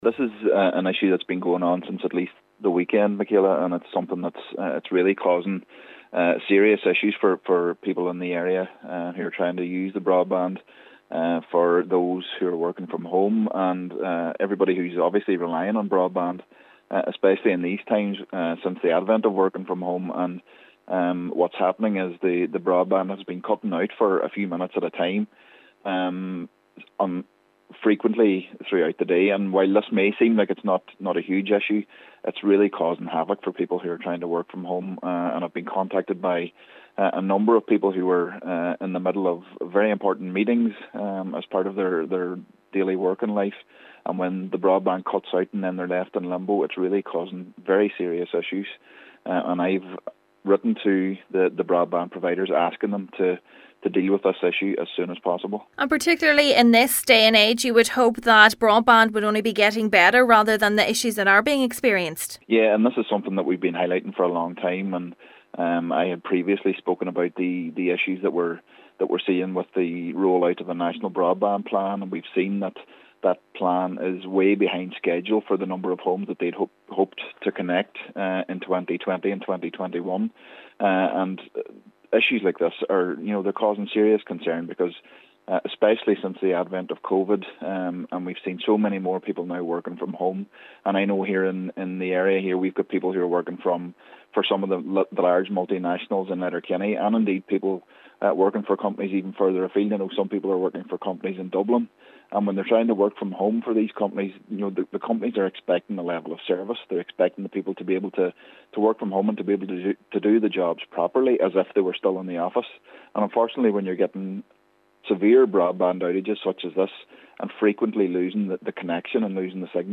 Councillor Doherty says the broadband providers need to urgently resolve the issue: